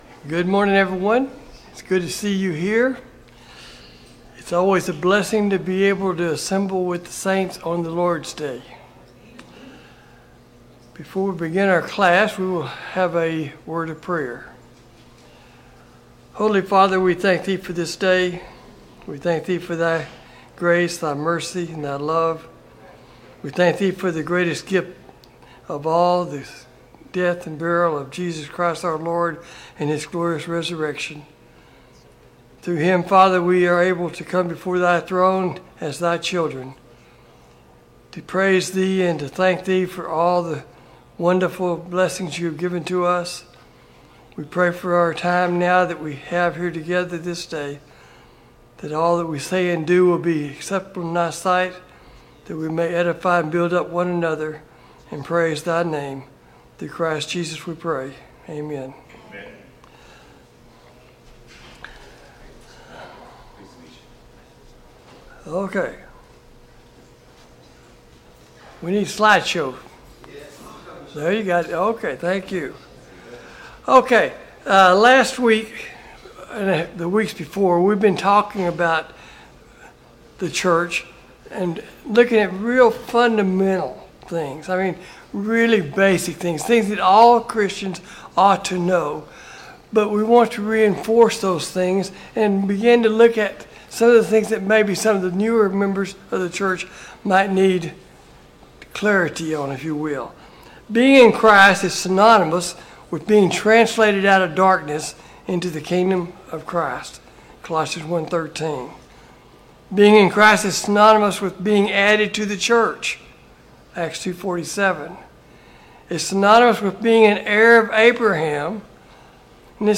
Sunday Morning Bible Class « Study of Paul’s Minor Epistles